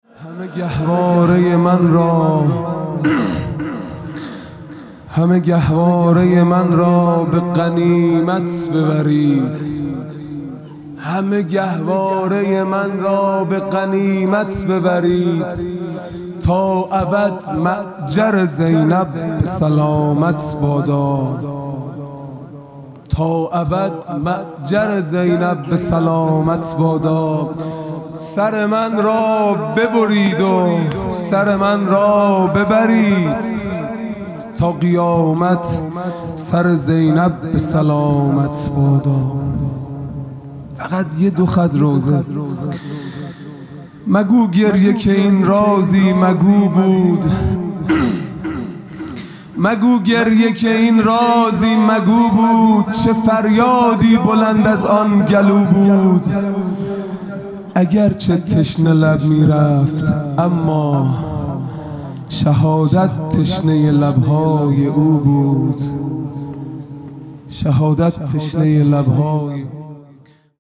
خیمه گاه - هیئت بچه های فاطمه (س) - مرثیه | همه گهوارۀ من را به غنیمت ببرید